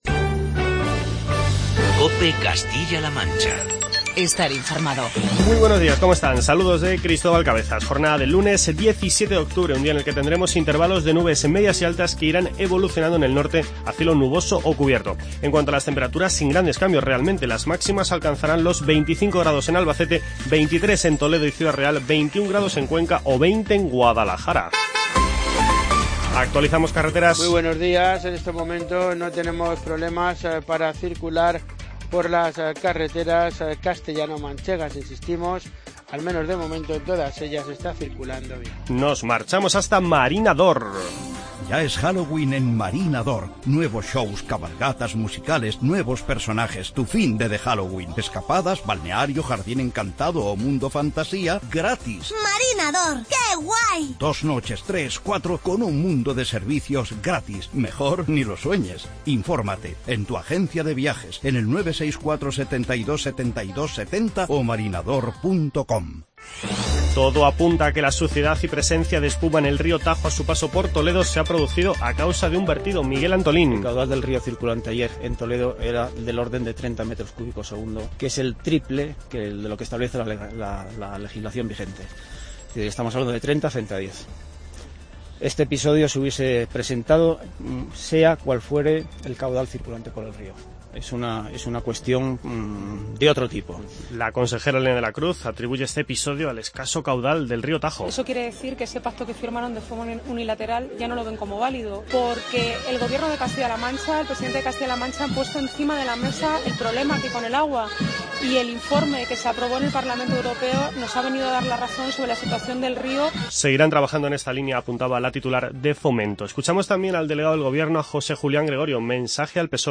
Informativo COPE Castilla-La Manca